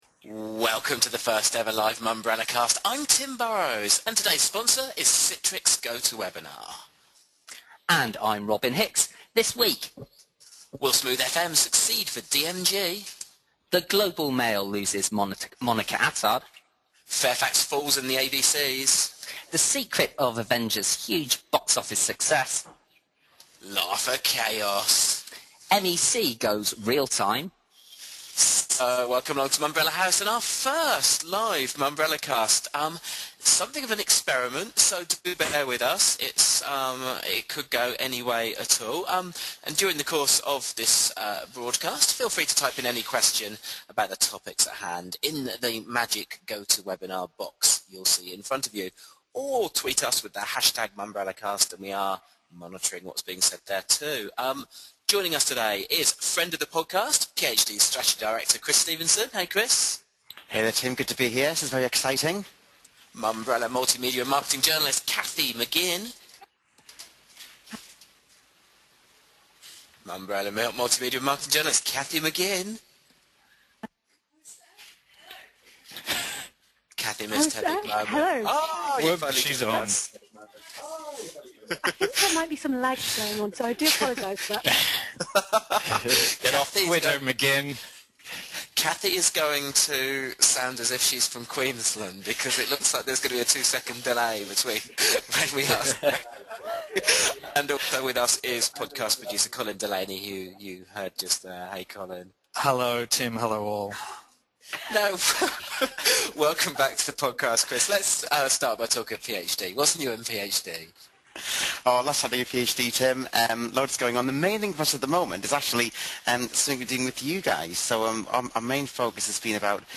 The first ever live Mumbrellacast was streamed at 12.30 EST Friday 11 May.
live-podcast.mp3